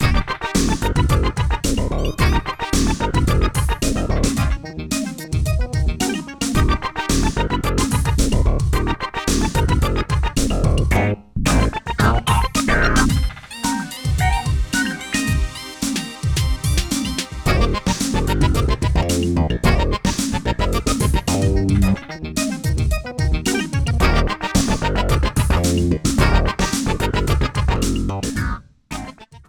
Fair use music sample